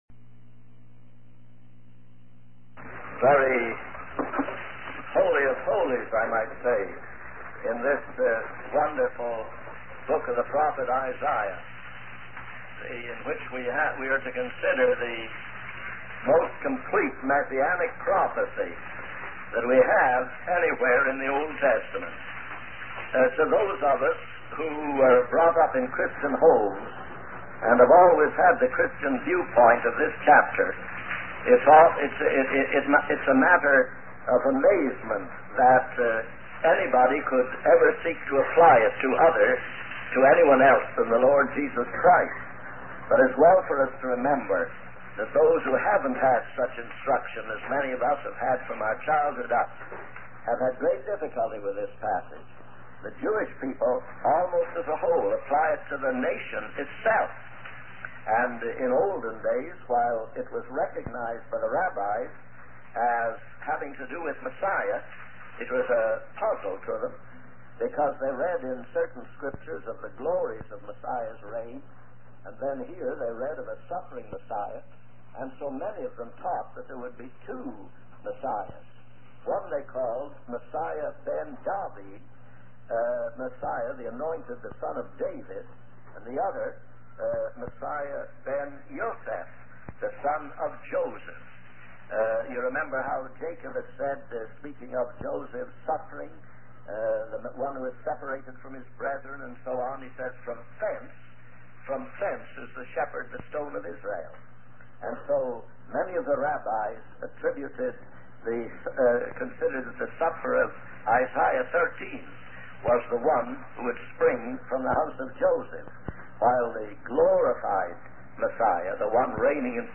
In this sermon transcript, the speaker reflects on a text that he has been trying to preach for sixty years.